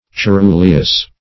ceruleous - definition of ceruleous - synonyms, pronunciation, spelling from Free Dictionary Search Result for " ceruleous" : The Collaborative International Dictionary of English v.0.48: Ceruleous \Ce*ru"le*ous\, a. Cerulean.